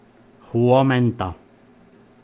Ääntäminen
Synonyymit hyvää huomenta Ääntäminen Tuntematon aksentti: IPA: /ˈhuɔ.men.tɑ/ Haettu sana löytyi näillä lähdekielillä: suomi Käännös Huudahdukset 1. guten Morgen Huomenta on sanan huomen partitiivi.